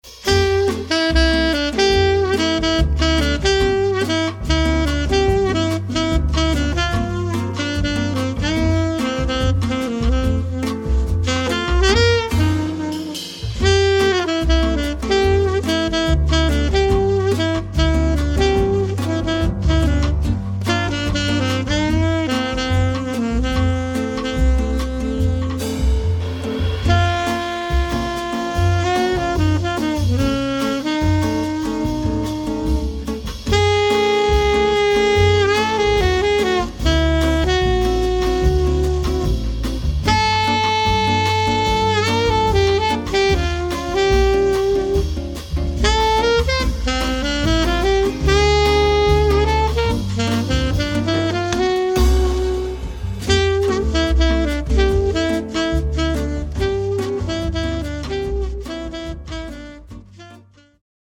Instrumental Rat-Pack Jazz Band
(4-piece)